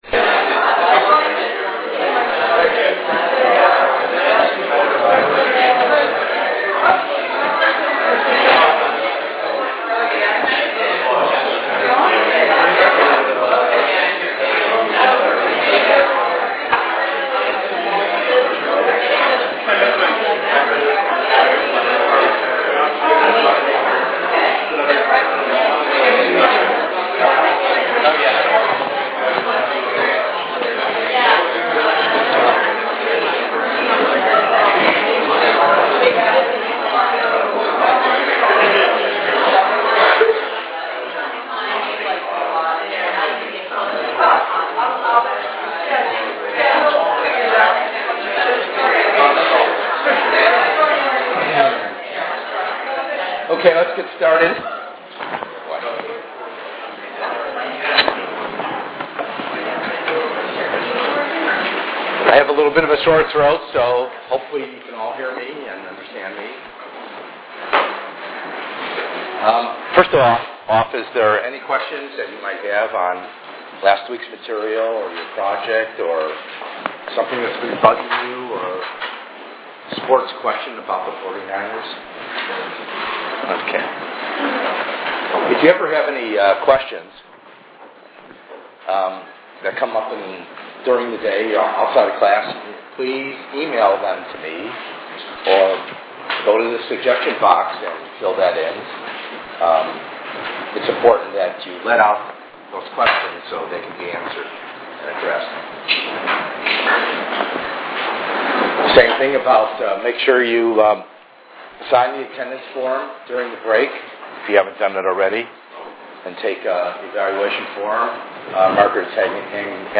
In this panel discussion, several Stanford students with disabilities will discuss their disabilities, academic goals, the assistive technology they use to be successful students, and the challenges they have faced.